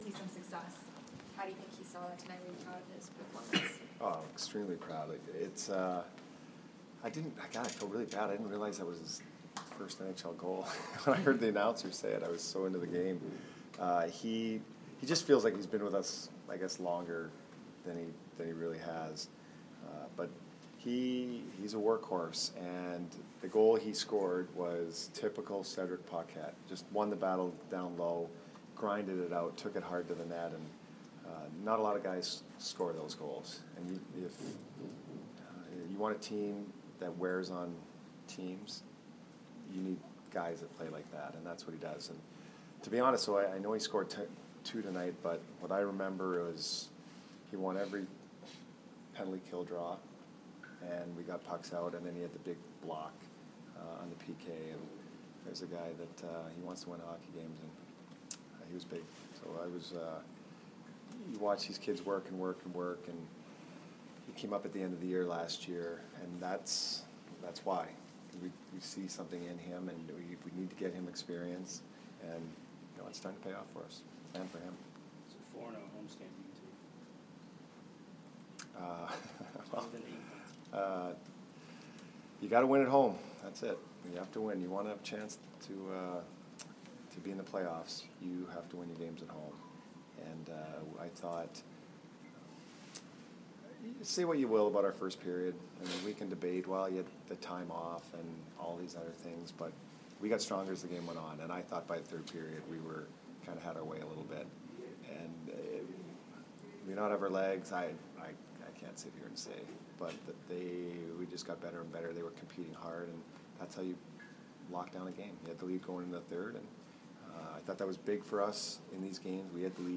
Cooper post-game 11/6 vs. CGY